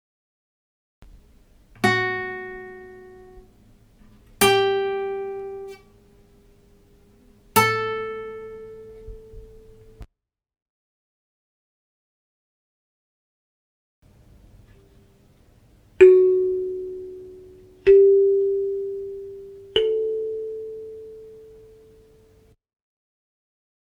guitarrakalimba.mp3